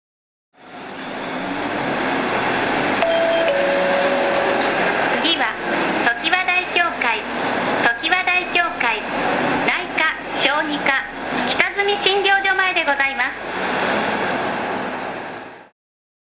車内アナウンスコレクション
テープ時代からメロディーチャイムを使用しており何度かチャイムやアナウンサーが変わってきましたが、音声合成装置化してから一貫して同じチャイムを使用してます。